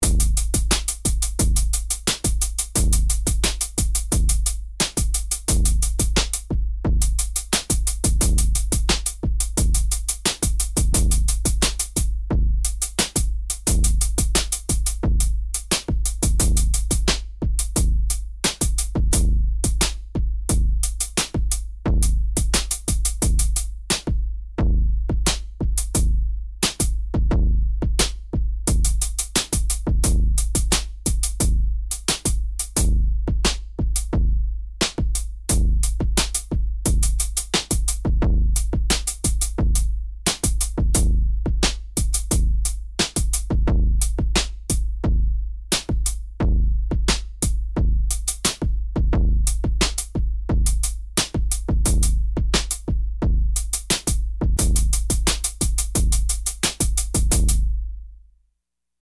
NOTE: The snare lands on the 2nd and 4th beat, and remains the same throughout this entire article.
Lowering the Density of a track effectively decreases the probability of every hit in the pattern at the same time.
7_Hat-Density.mp3